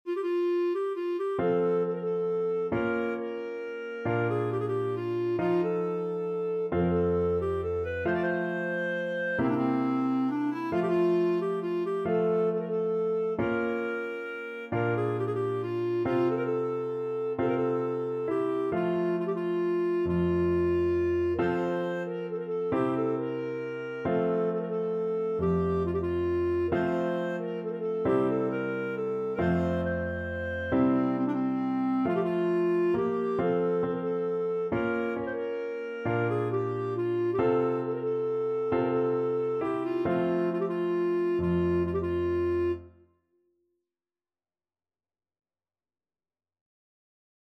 Clarinet
Traditional Music of unknown author.
F major (Sounding Pitch) G major (Clarinet in Bb) (View more F major Music for Clarinet )
One in a bar .=45
3/4 (View more 3/4 Music)
C5-C6
romania_joc_in_patru_CL.mp3